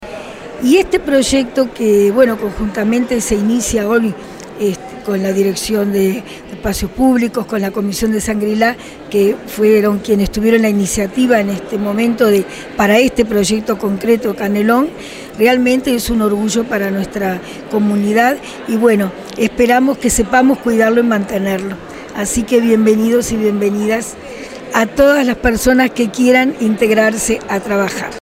Este sábado 25 de junio se presentó en el Hall del Centro Cívico Costa Urbana el proyecto Canelón, una iniciativa de la Comisión Pro Fomento de Shangrilá que cuenta con el apoyo de la Intendencia de Canelones y del Municipio de Ciudad de la Costa.
sonia_misirian_alcaldesa_ciudad_de_la_costa.mp3